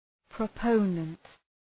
{prə’pəʋnənt}
proponent.mp3